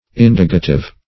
Search Result for " indagative" : The Collaborative International Dictionary of English v.0.48: Indagative \In"da*ga*tive\, a. Searching; exploring; investigating.
indagative.mp3